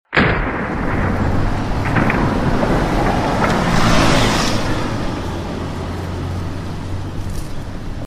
Traffic On The Road — Sound Effects Free Download